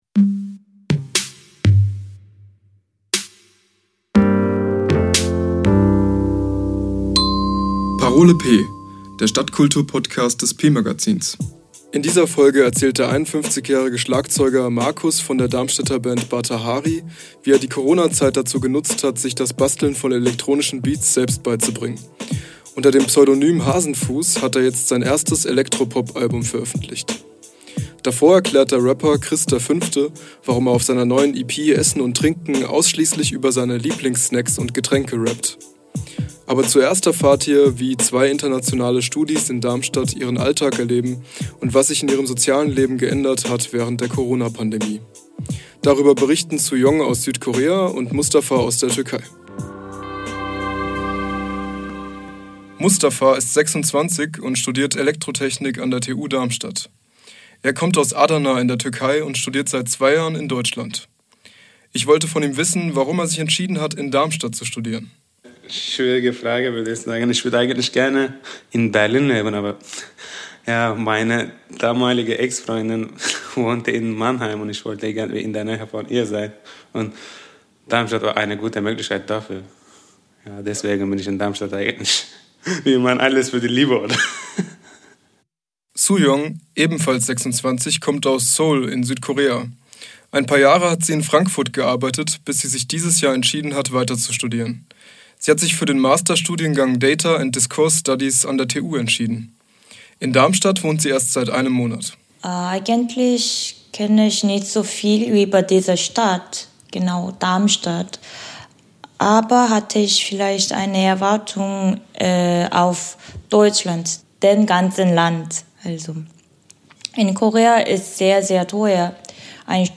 Im Interview